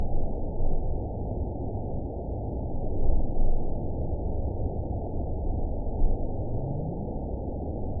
event 920425 date 03/24/24 time 09:38:15 GMT (1 year, 1 month ago) score 9.34 location TSS-AB07 detected by nrw target species NRW annotations +NRW Spectrogram: Frequency (kHz) vs. Time (s) audio not available .wav